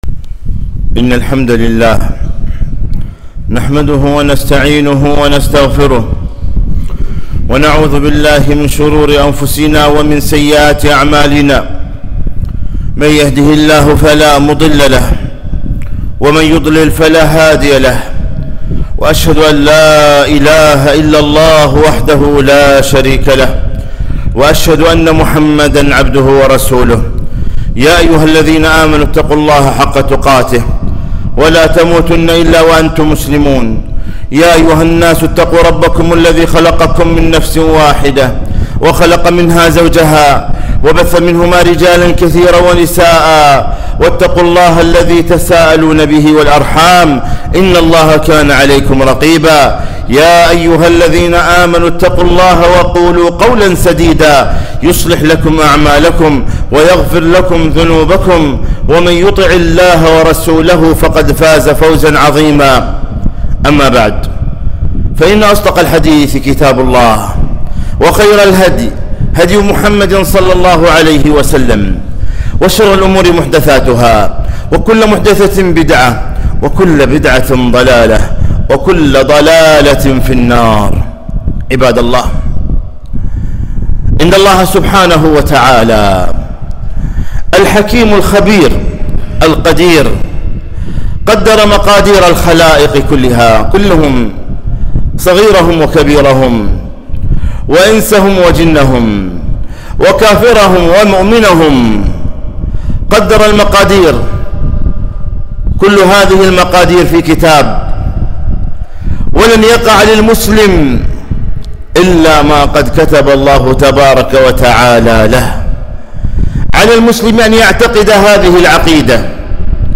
خطبة - لا تعترض على شيء اختاره الله لك